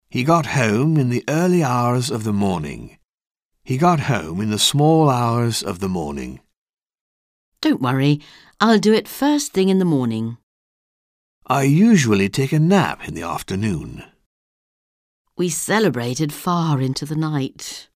Un peu de conversation - Le jour et la nuit